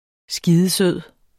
Udtale [ ˈsgiːðəˈsøðˀ ]